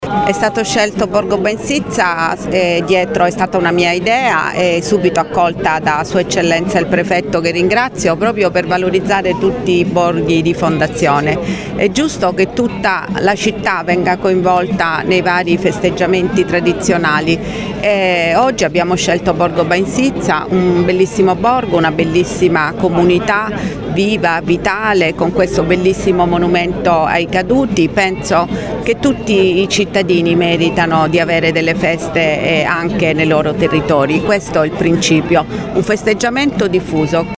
Questo borgo, nato per la bonifica e per dare una terra e una nuova vita ai reduci e ai coloni venuti da lontano, è un monumento vivente al sacrificio e alla rinascita”, ha detto la prima cittadina.